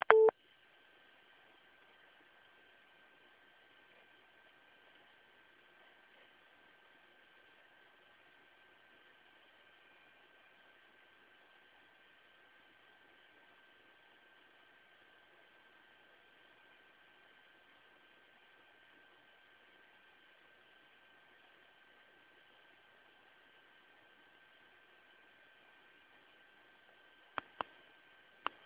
umiarkowanej głośności.